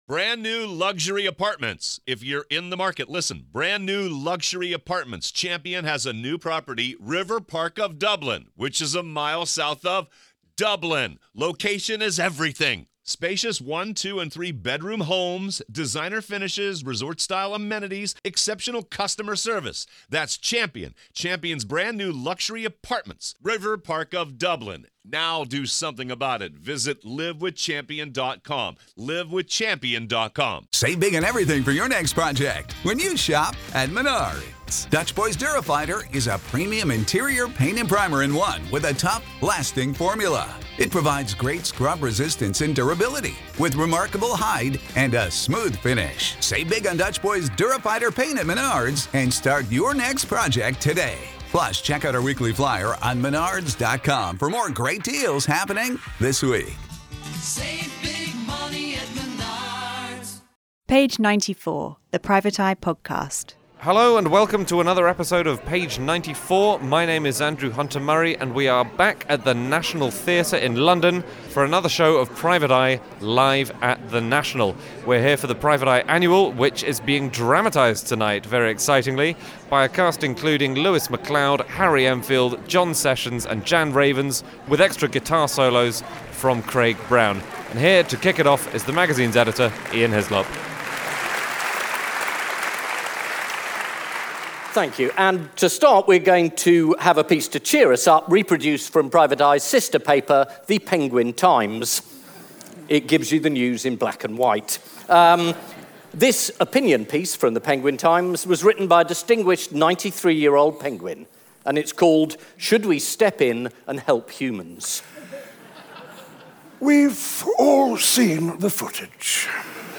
40: Live at the National Page 94: The Private Eye Podcast Page 94: The Private Eye Podcast News 4.6 • 1.6K Ratings 🗓 10 December 2018 ⏱ 27 minutes 🔗 Recording | iTunes | RSS 🧾 Download transcript Summary Episode 40. A star-studded cast of Harry Enfield, John Sessions, Jan Ravens and Lewis MacLeod join editor Ian Hislop for a live show at the National Theatre looking back at the year's news.